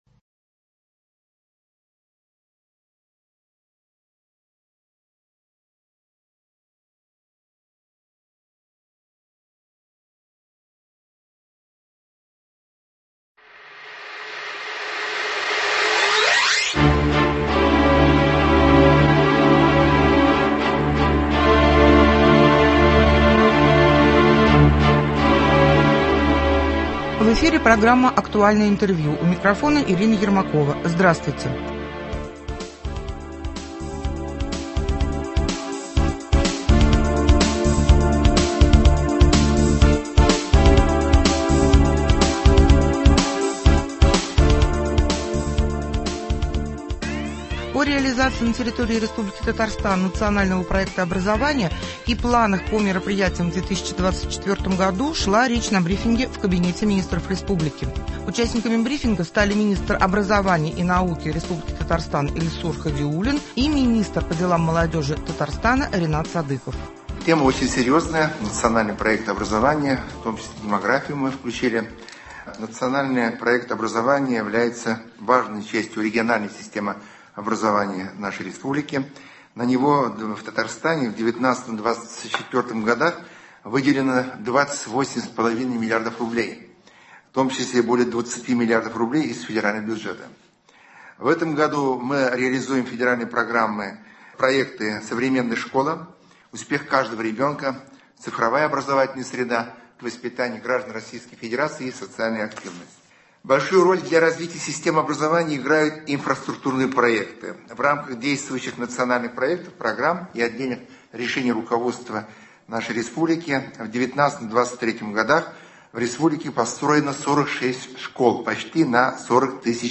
Актуальное интервью (06.03.24)